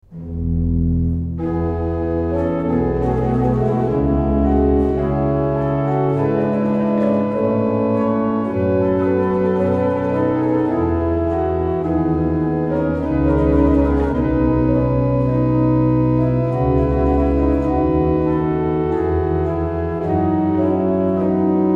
choral pour orgue
BACH, choral pour orgue (chorals de leipzig) - LEROLLE, la repetition a la tribune.mp3